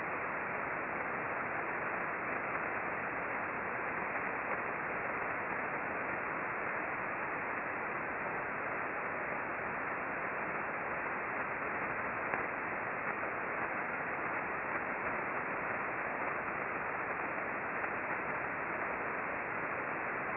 We observed mostly S-bursts (popping sound) during the periods mentioned above.
The sound file for the bursting period above is very weak